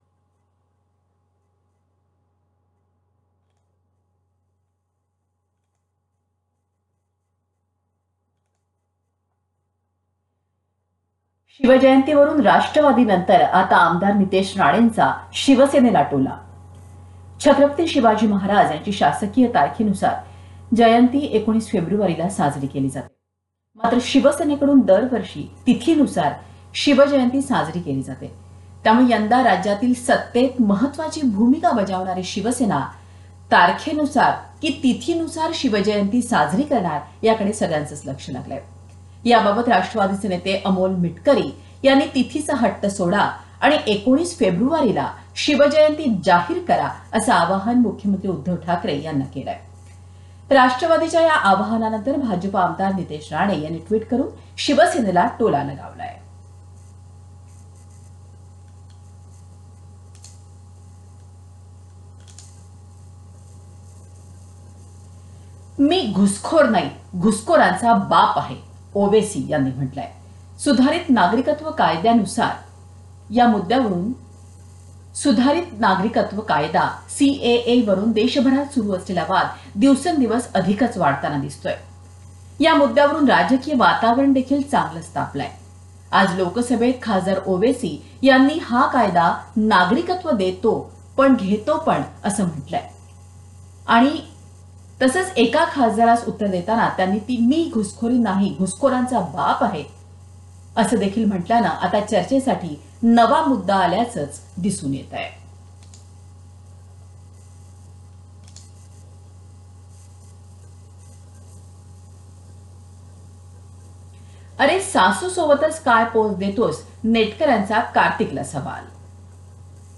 News Headlines 4th feb